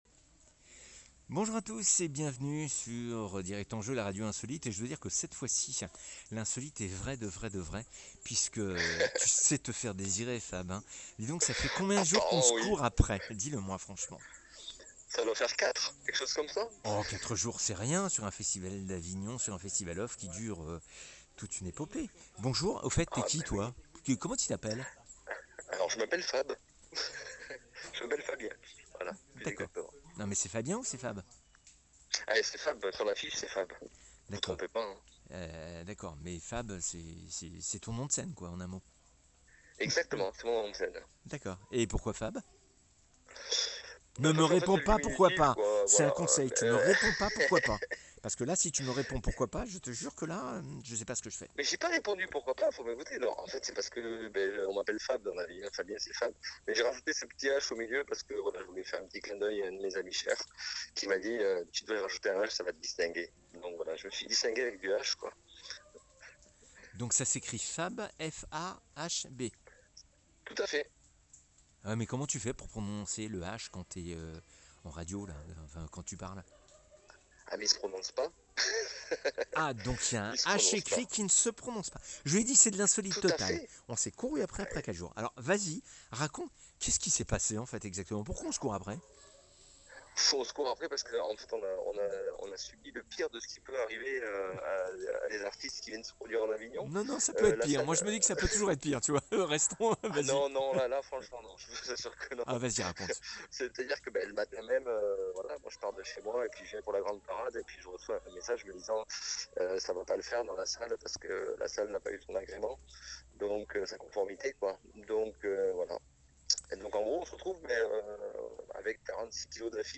La CHANSON!